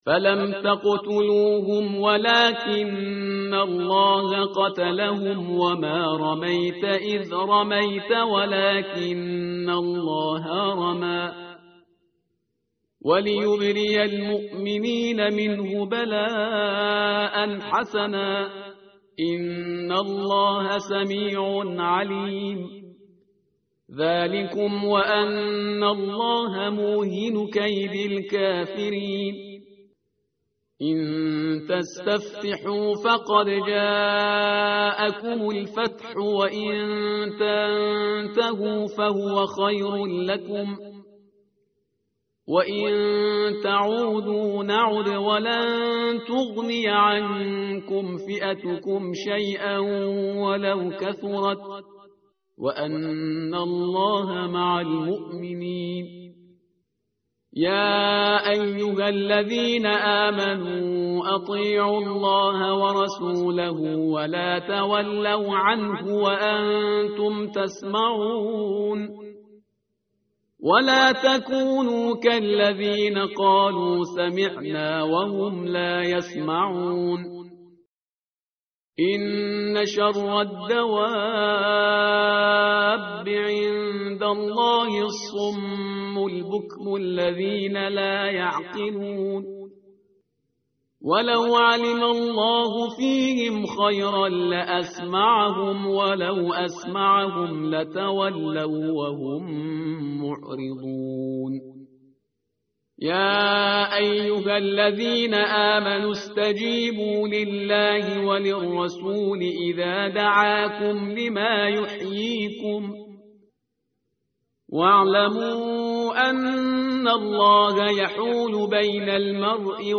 متن قرآن همراه باتلاوت قرآن و ترجمه
tartil_parhizgar_page_179.mp3